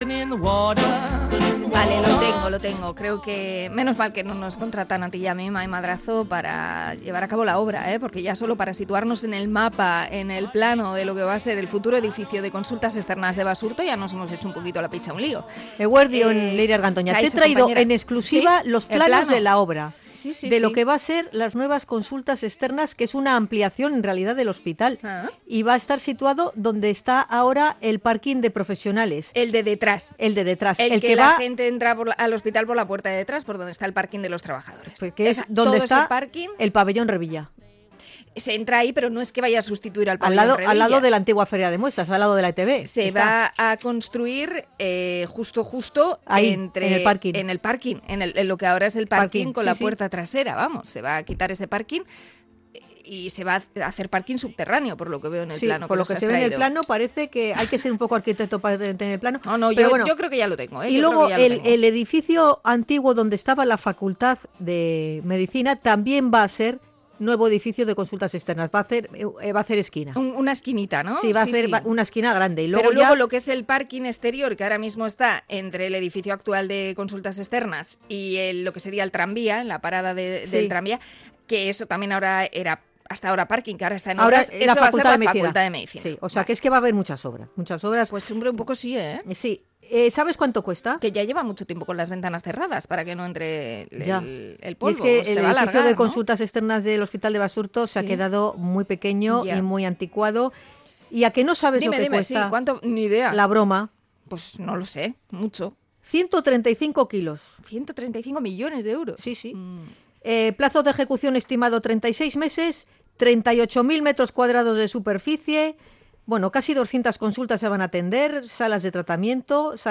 Crónica tras la rueda de prensa de Sanidad del nuevo edificio en el hospital de Basurto